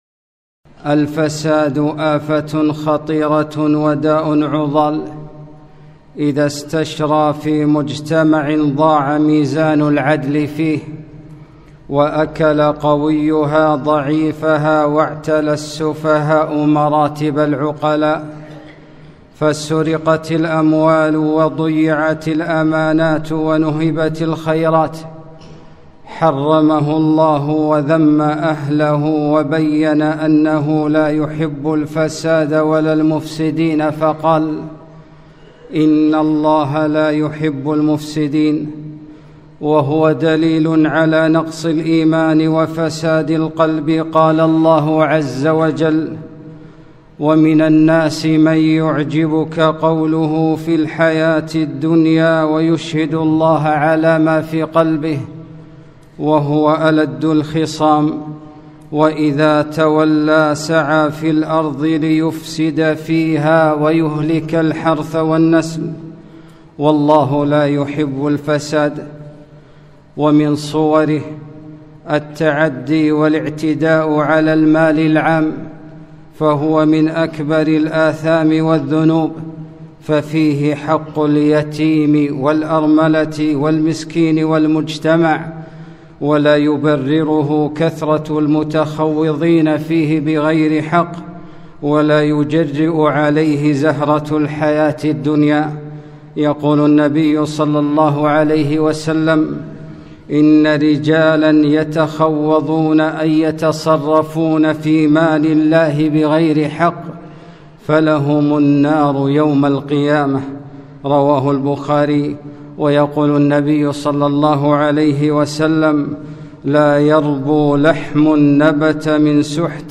خطبة - حرمة المال العام